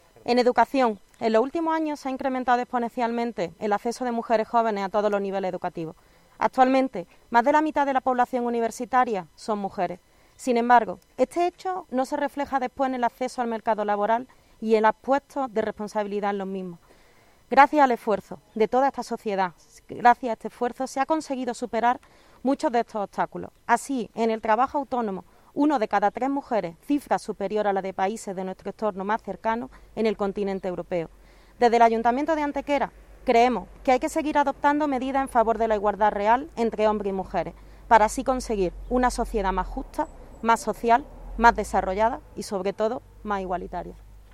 El teniente de alcalde delegado de Igualdad, Alberto Arana, y la concejal de Equidad, Sara Ríos, han leido ante los medios de comunicación dicho texto al no poder realizarse concentraciones masivas debido a las restricciones sanitarias de la pandemia.
Cortes de voz